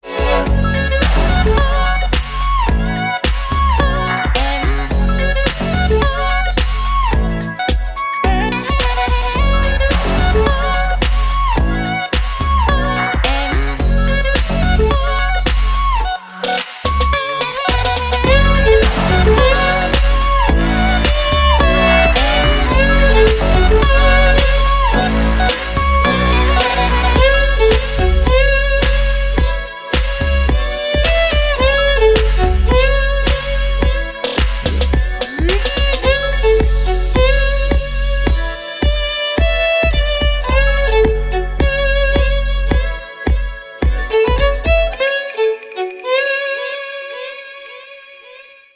без слов
скрипка